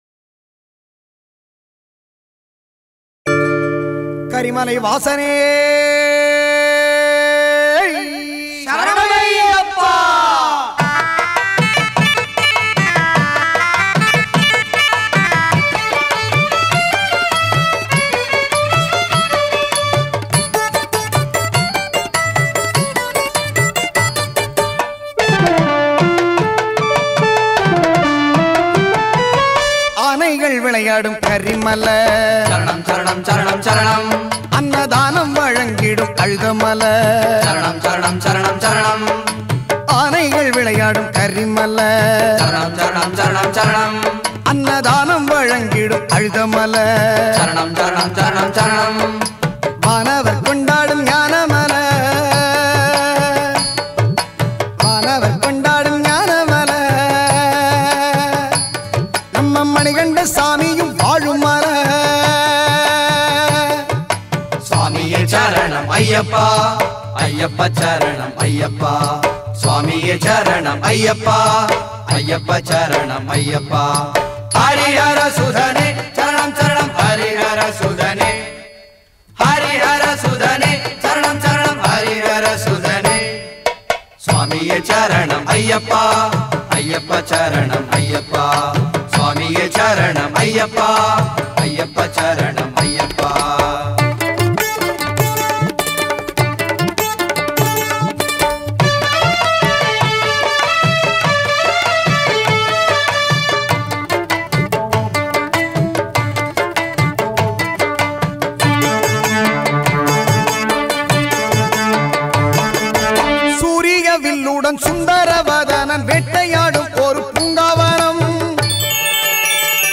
ayyappan devotional album songs